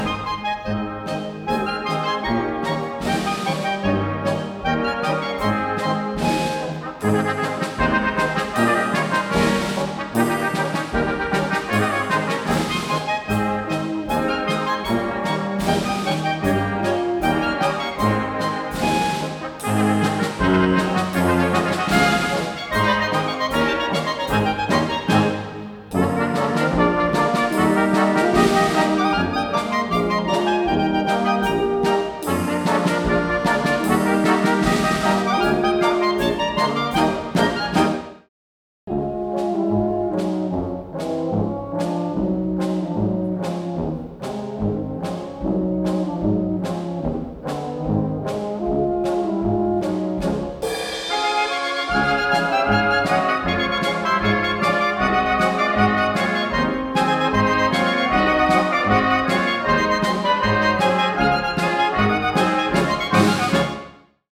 für Blaskapelle (Kleine Besetzung)